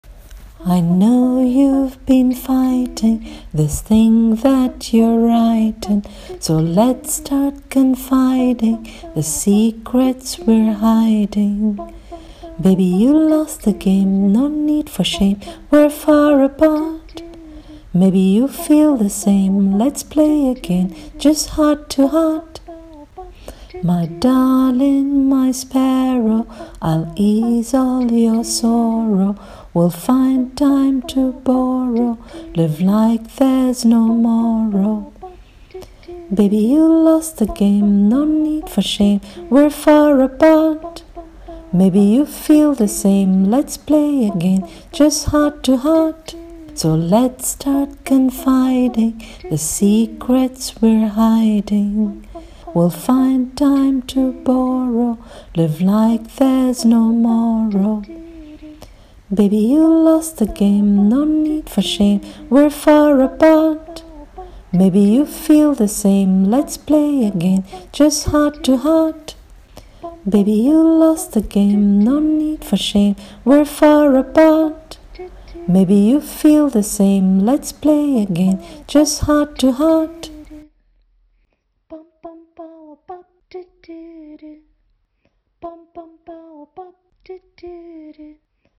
Singing of the lyrics: Version 1: